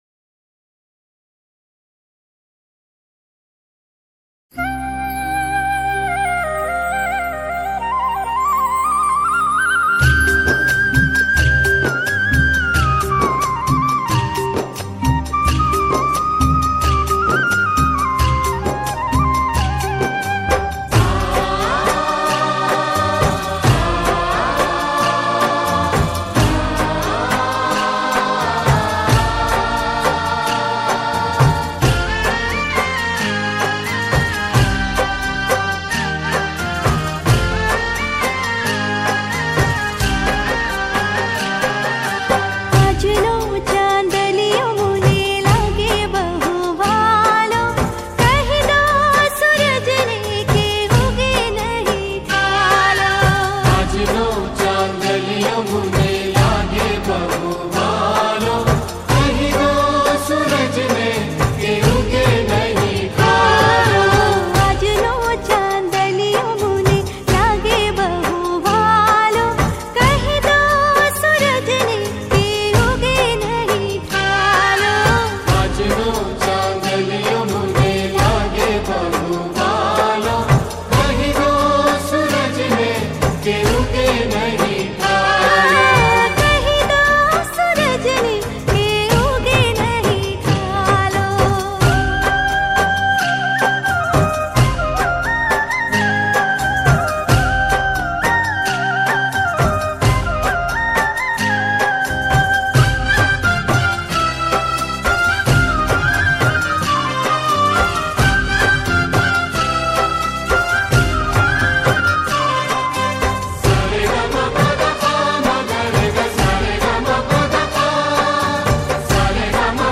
ગીત સંગીત ગરબા - Garba
Gujarati Garba-Ras